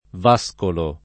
vascolo [ v #S kolo ]